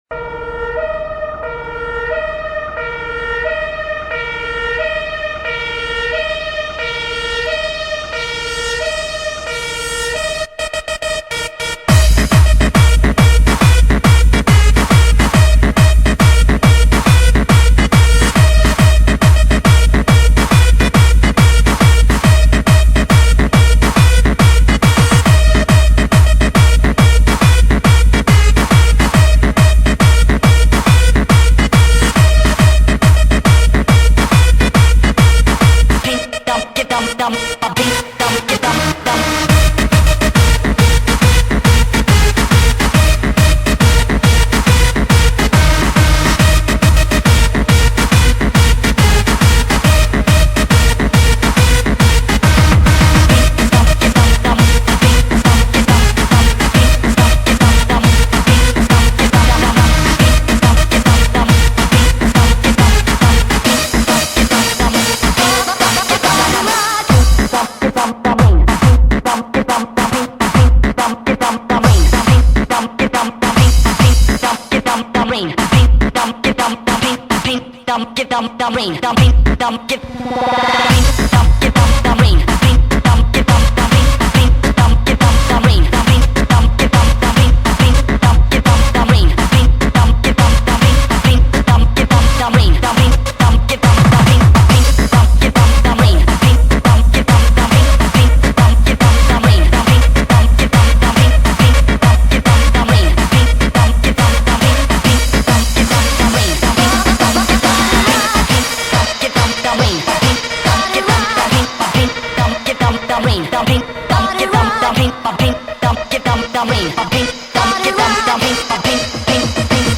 آهنگ سیستمی پلیسی شوتی خطر ریمیکس